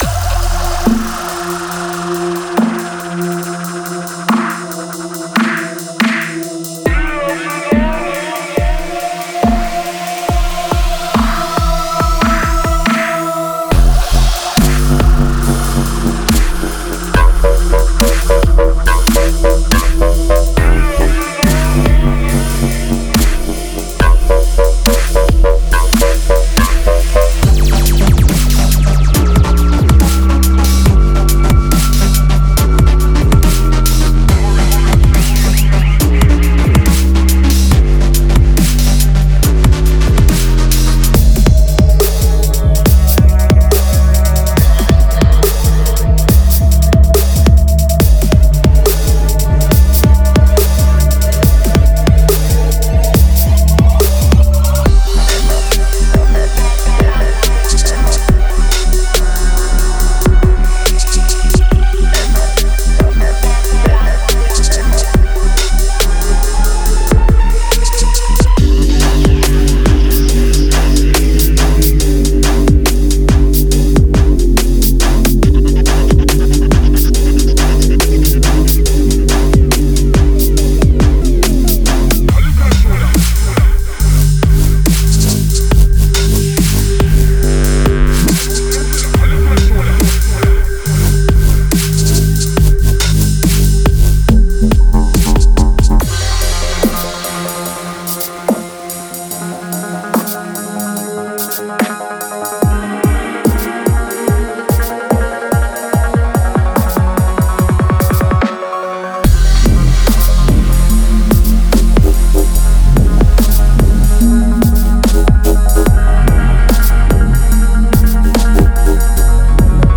Genre:Dubstep
デモサウンドはコチラ↓